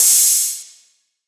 SOUTHSIDE_open_hihat_nobeginning.wav